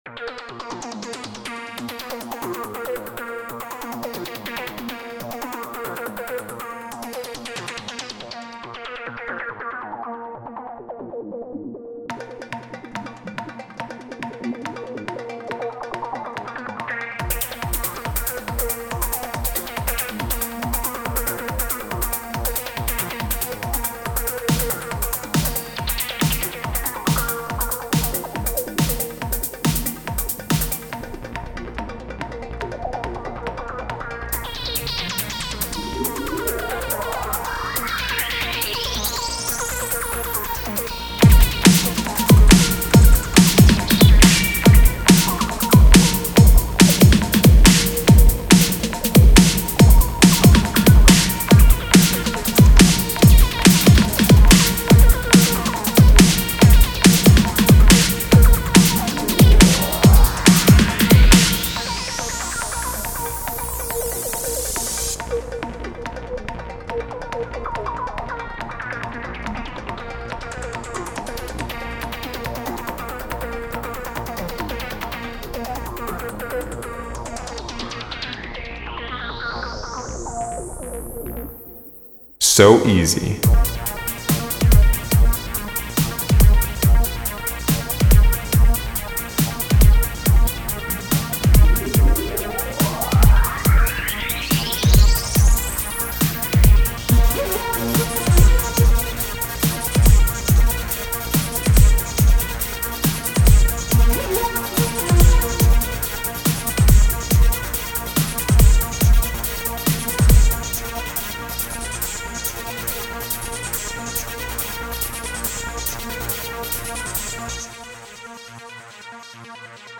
A fast paced futuristic music, I honestly don't really know in what kind of situation this music can be used 😂.